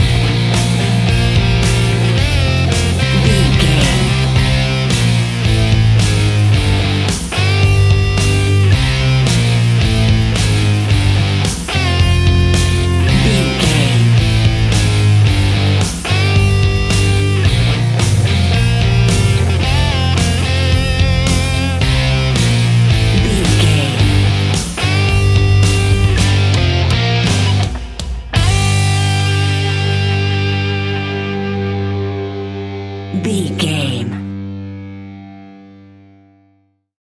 Epic / Action
Aeolian/Minor
hard rock
blues rock
Rock Bass
heavy drums
distorted guitars
hammond organ